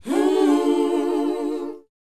WHOA C B.wav